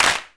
标签： 回路 电子 合成器 处理 打击乐器 实验
声道立体声